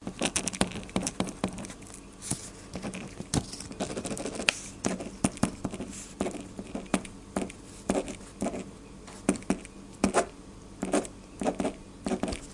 描述：笔在一些纸上涂鸦的声音效果